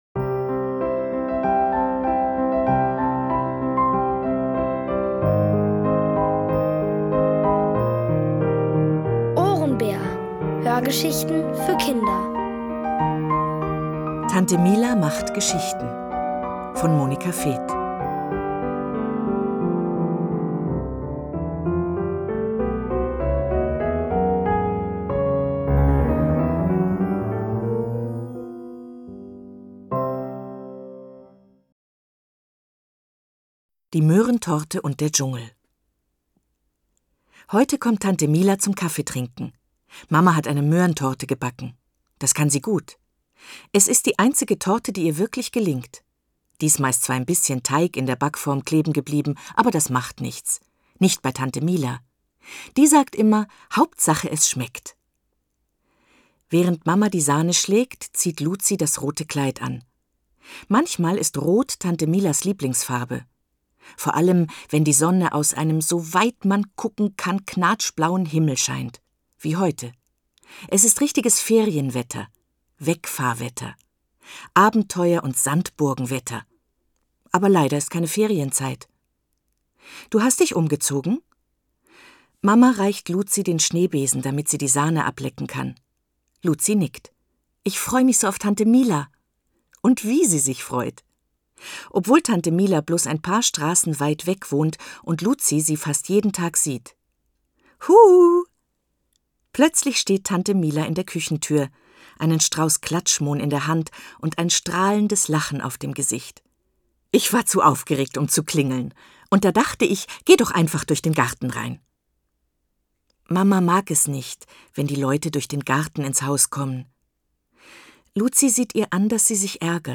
Von Autoren extra für die Reihe geschrieben und von bekannten Schauspielern gelesen.
Es liest: Eva Mattes.